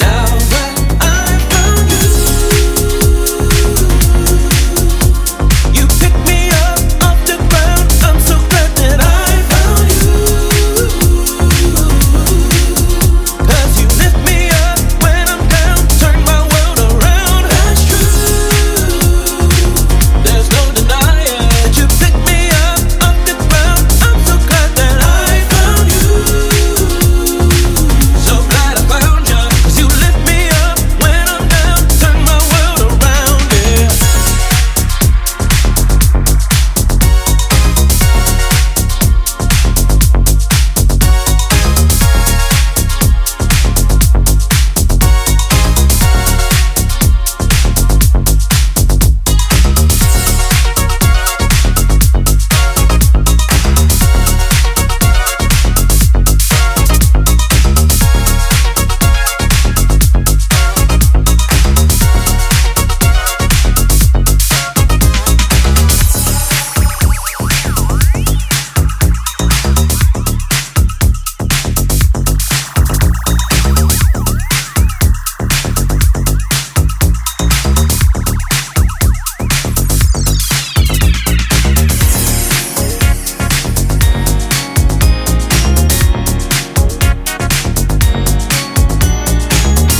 disco, funk and groove-laden